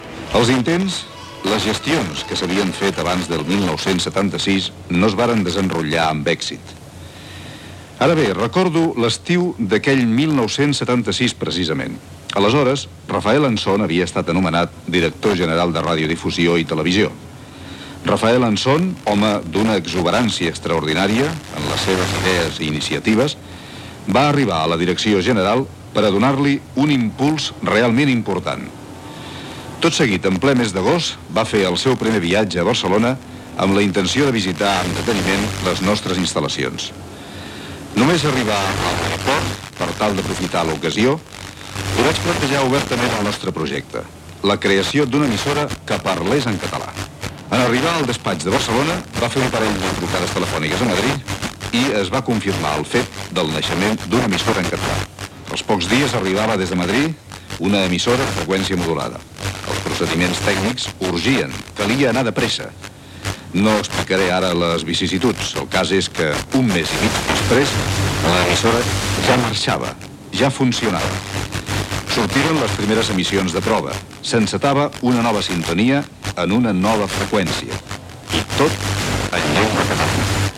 Fragmengt extret del program,a "Tarda cinc estrelles" emès per Ràdio 4 el 13 de desembre de 1990